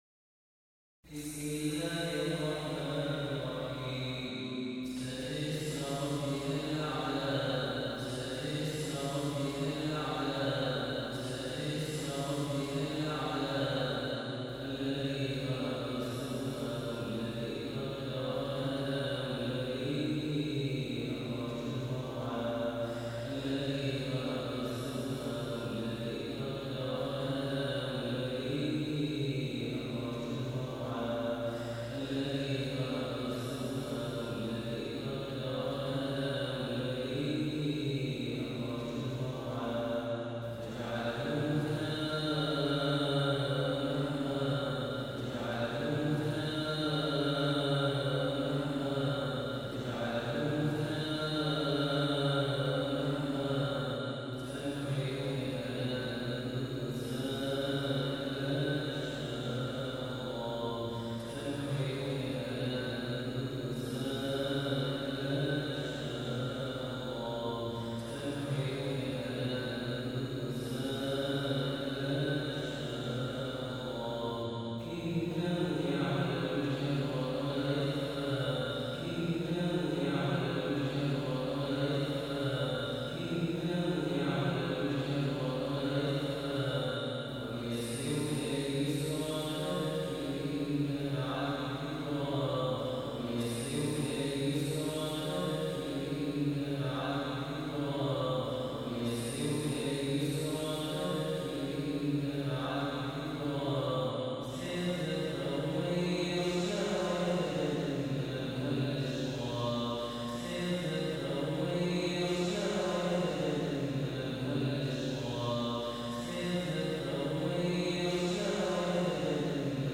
The Holy Quran recitation for Famous readers to listen and download